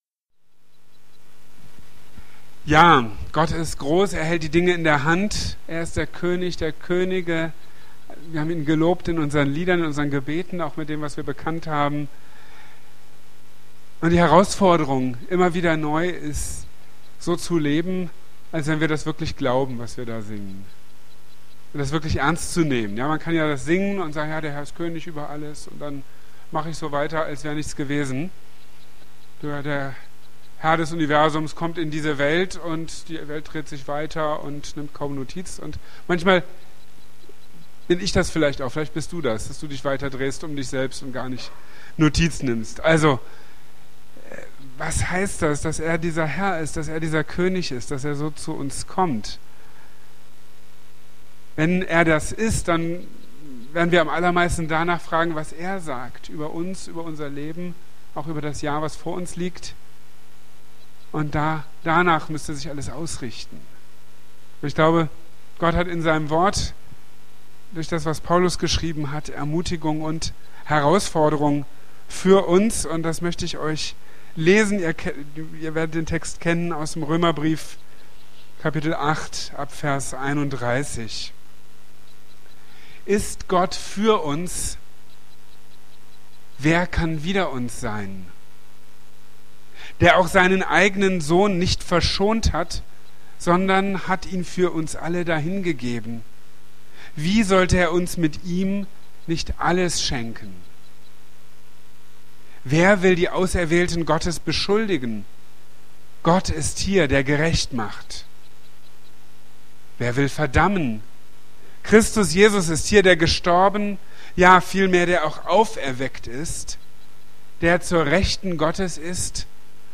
In Gottes Liebe geborgen | Marburger Predigten